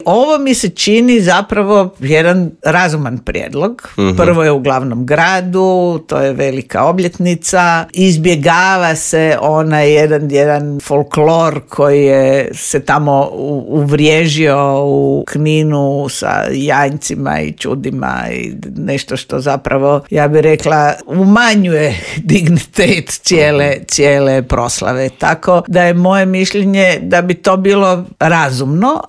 U Intervjuu Media servisa ugostili smo bivšu ministricu vanjskih i europskih poslova Vesnu Pusić koja kaže da je civilno društvo u Srbiji pokazalo da tamo postoji demokratska javnost što se poklopilo sa zamorom materijala vlasti: